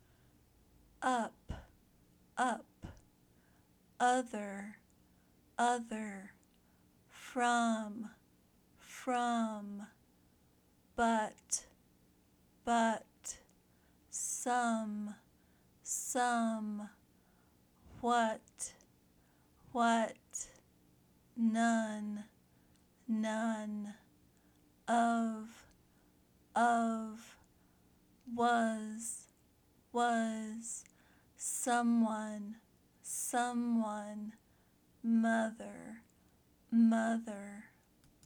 Practice the Short “U” Sound